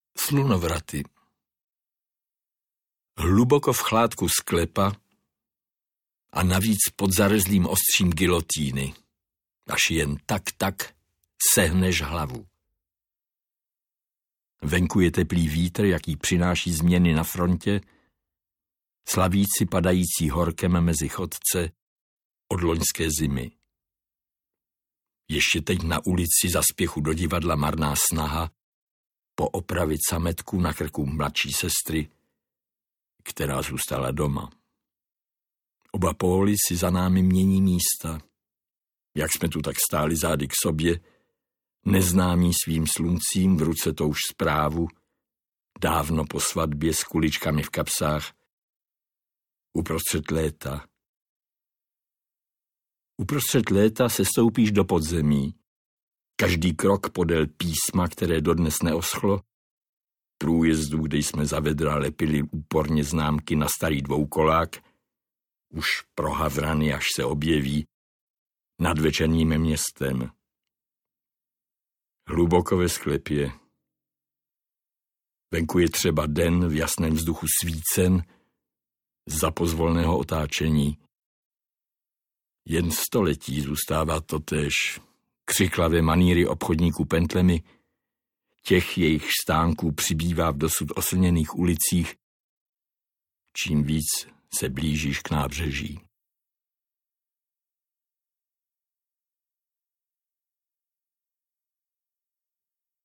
HLASY - Petr Král audiokniha
V této audioknize čte básník Petr Král z vlastních nepublikovaných sbírek z let 1958–1961, básnický cyklus Průběh sezóny, prózy Hlasy a Přejít ulici z knihy Základní pojmy a výňatky z básnického cyklu Přesuny.
Ukázka z knihy
• InterpretPetr Král